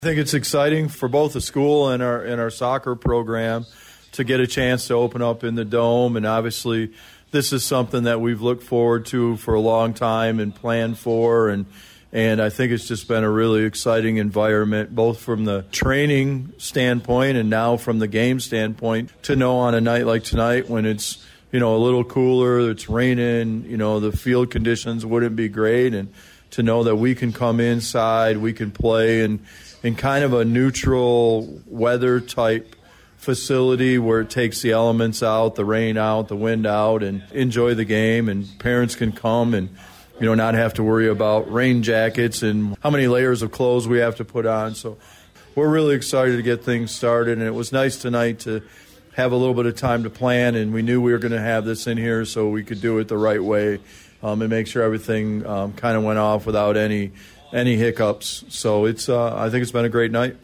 had this to say about playing the soccer match in the 11 million dollar facility that opened this summer…